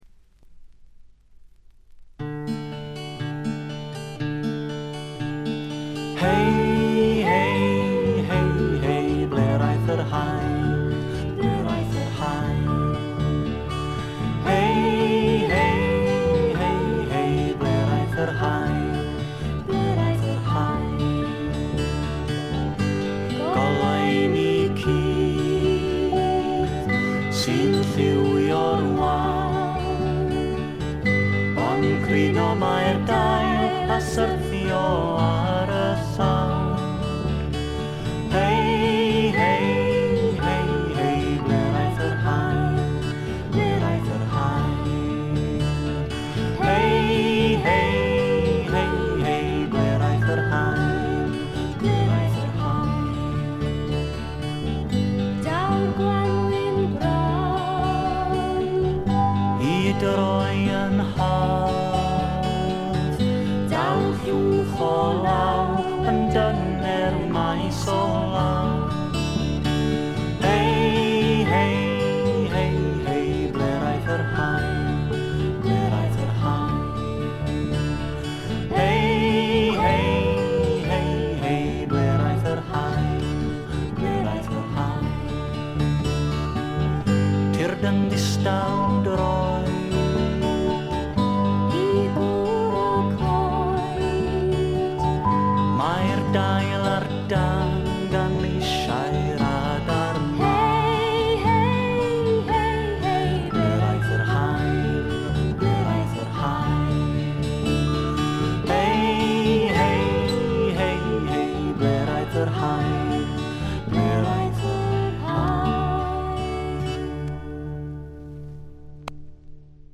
B5終了と同時にプツ音。
内容はフォーク、アシッド、ポップ、ロックと様々な顔を見せる七変化タイプ。
試聴曲は現品からの取り込み音源です。